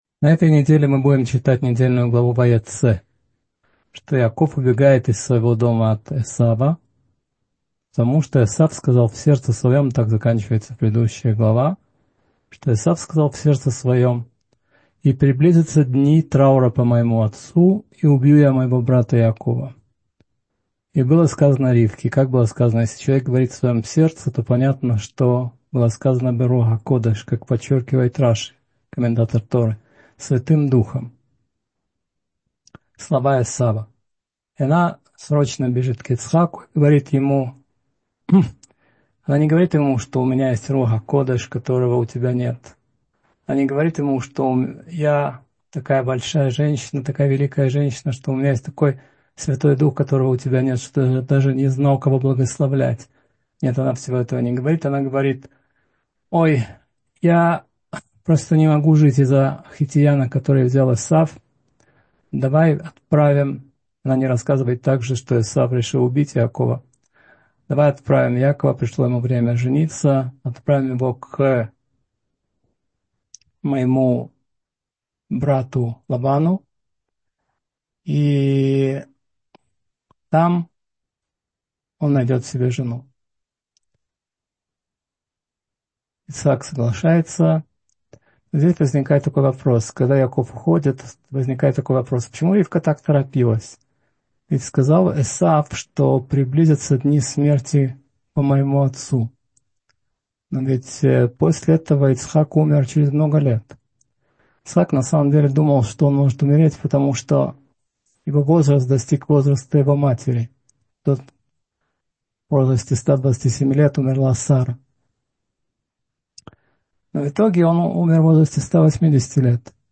Вайеце — слушать лекции раввинов онлайн | Еврейские аудиоуроки по теме «Недельная глава» на Толдот.ру